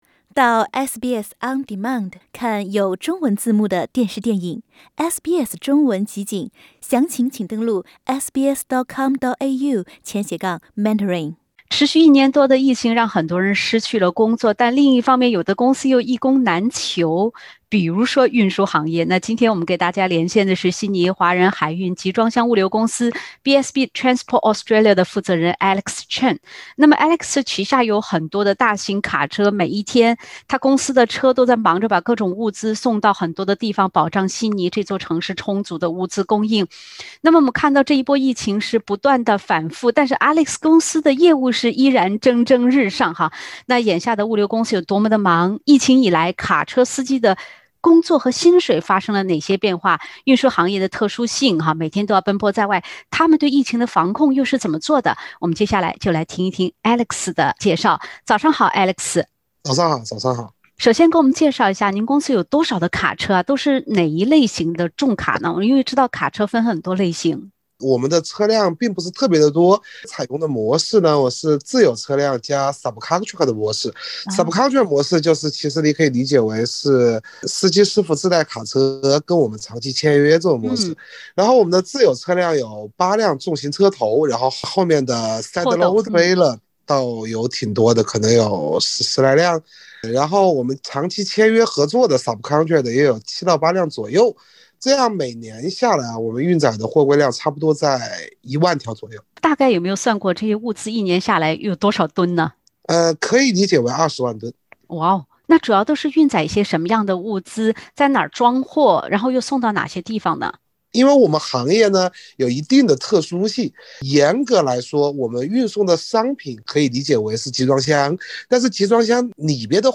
疫情下卡车司机薪酬如何？物流公司又受疫情影响了吗？（点击封面图片，收听完整对话）